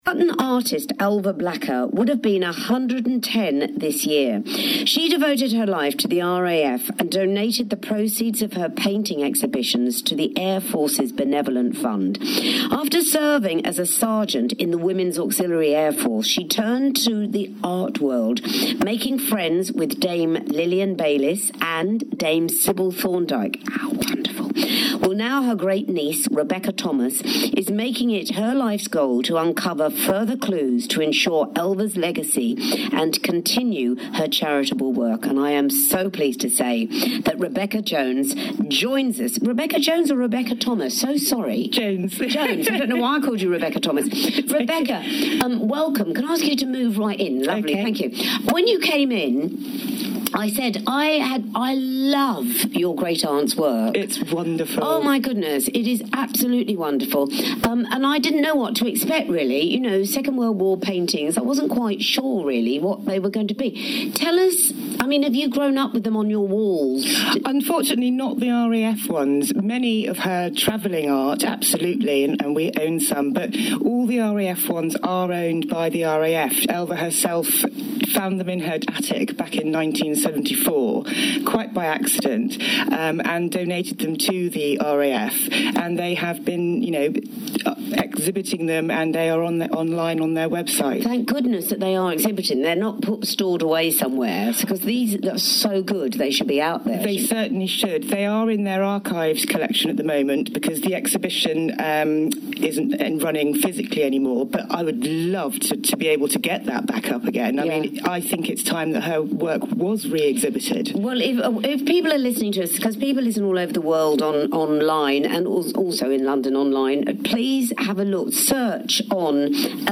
A recording from an appearance on BBC Radio.